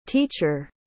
Schwa /ə/ | comic Anh Việt
teacher.mp3